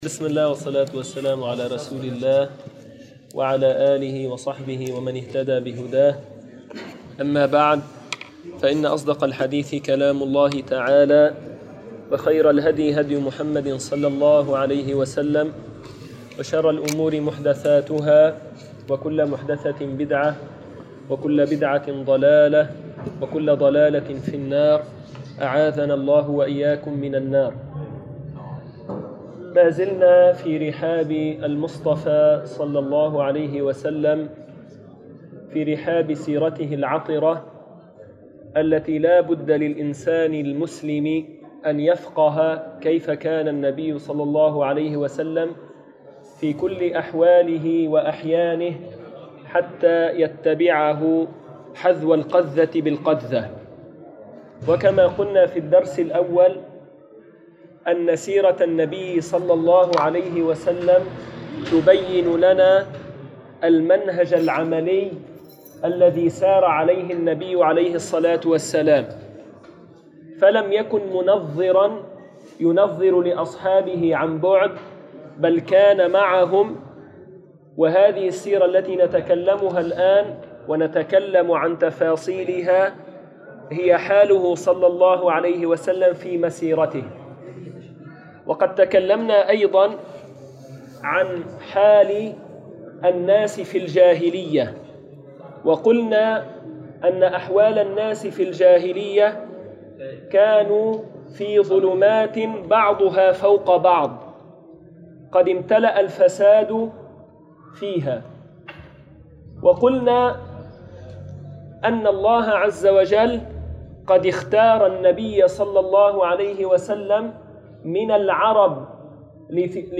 الموضوع: بدء نزول الوحي المكان: مسجد القلمون الغربي